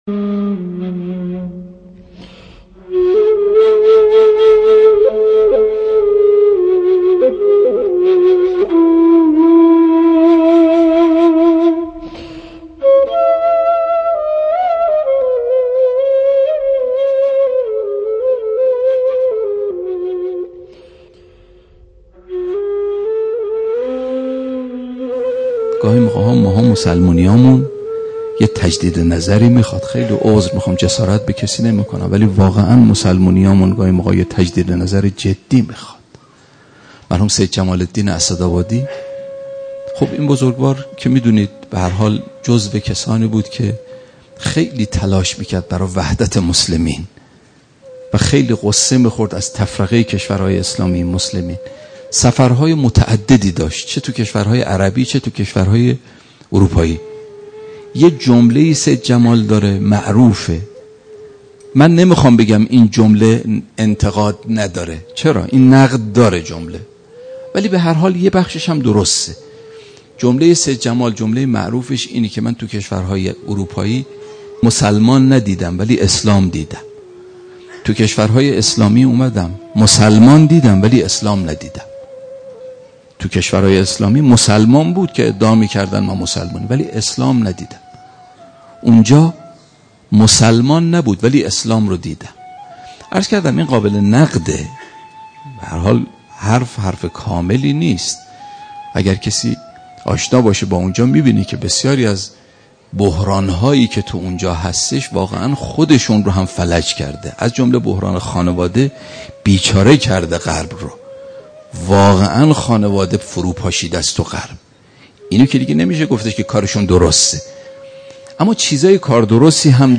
موضوع سخنرانی : مسلمانی